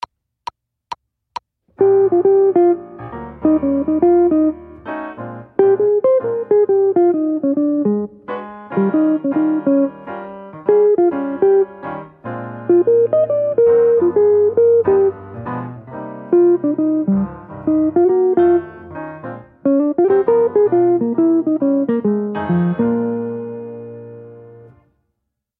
Méthode pour Guitare